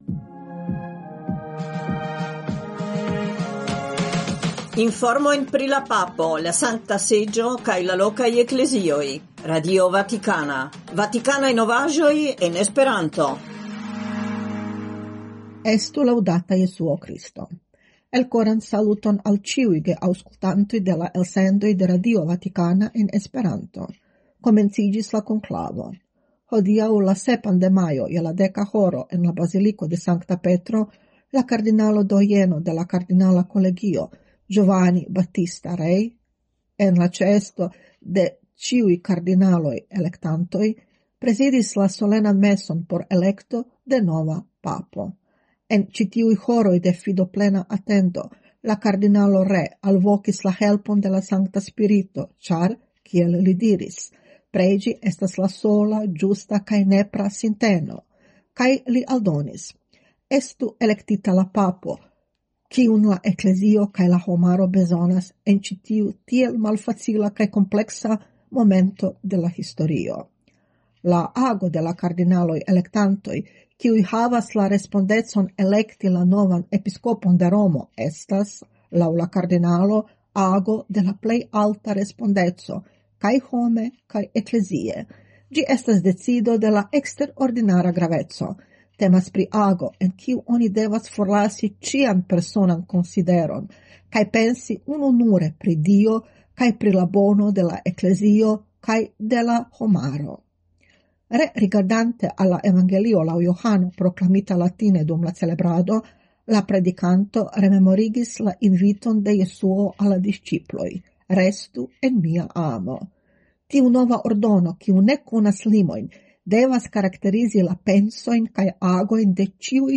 Elsendoj kun informoj el Vatikano en esperanto (trifoje semajne, merkrede, ĵaŭde kaj dimanĉe, horo 20.20 UTC). Ekde 1977 RV gastigas elsendojn en esperanto, kiuj informas pri la agado de la Papo, de la Sankta Seĝo, de la lokaj Eklezioj, donante spacon ankaŭ al internaciaj informoj, por alporti la esperon de la kredo kaj proponi interpreton de la faktoj sub la lumo de la Evangelio.